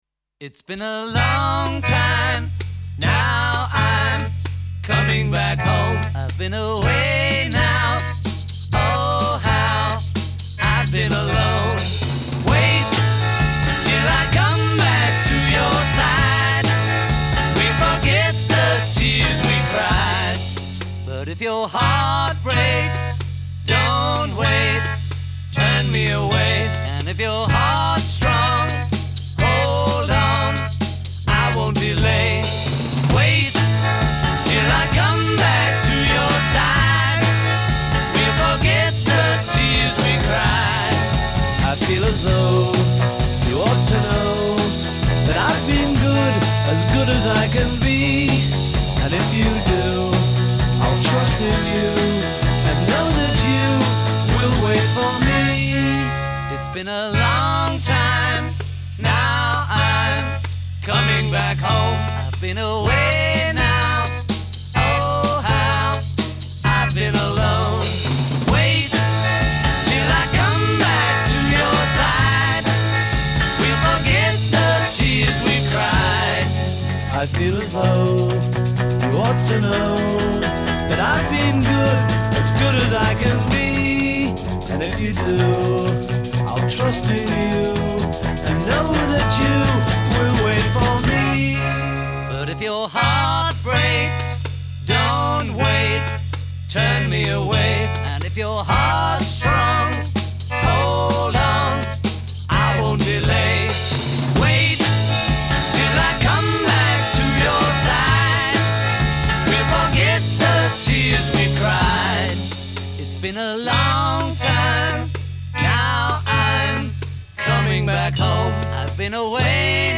Рок-н-ролл